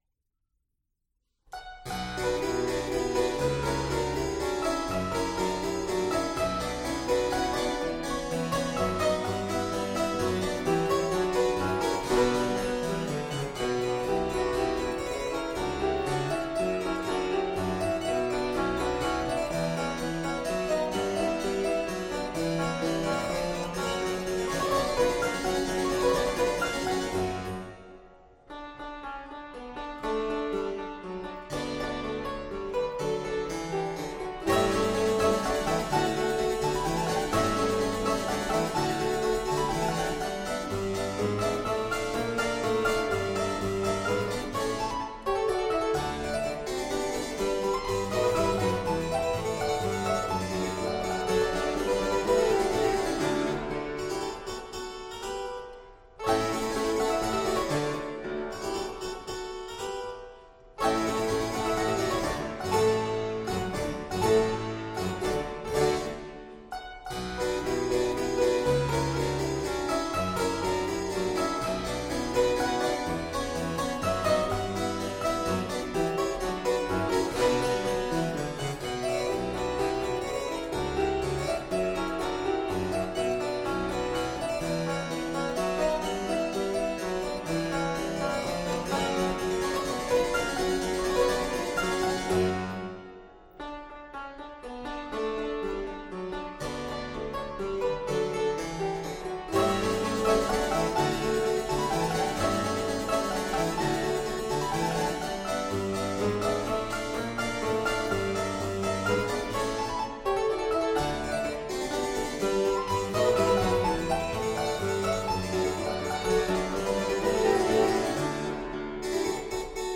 PerformerThe Raritan Players
Subject (lcsh) Duets